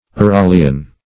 Uralian \U*ra"li*an\